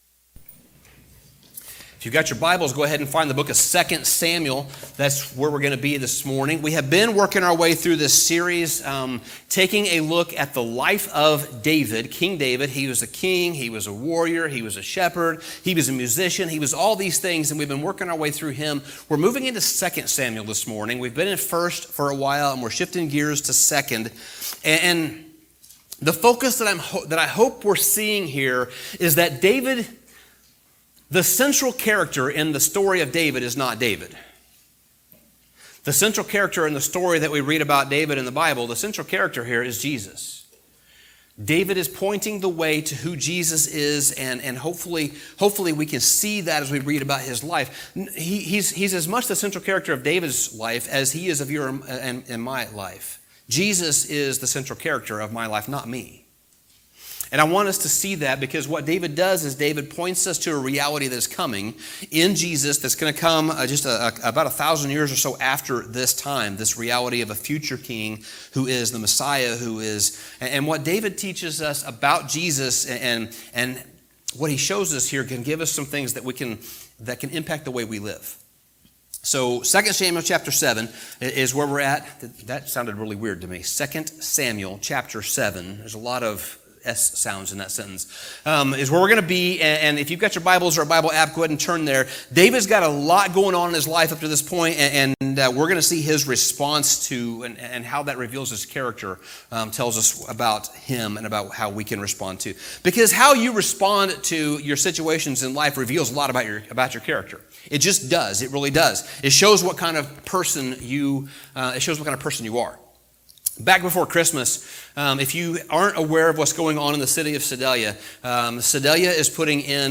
Sermon Summary After a long and eventful wait, David has finally become king.